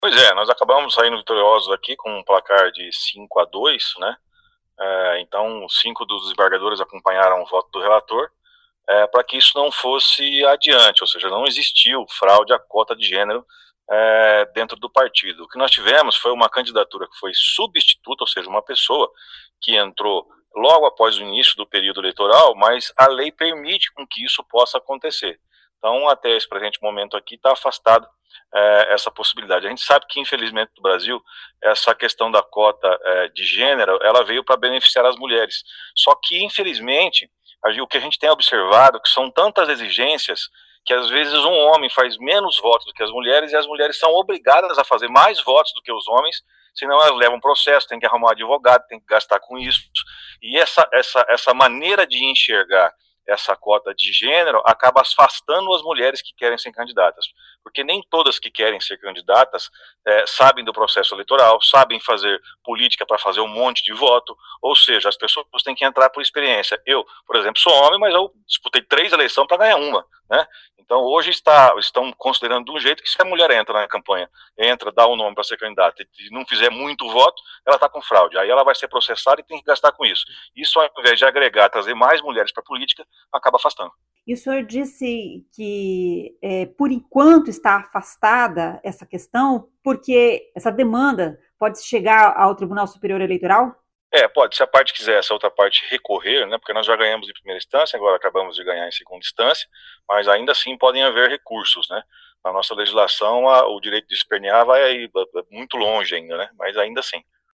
O vereador Flávio Mantovani acompanhou a votação em Curitiba.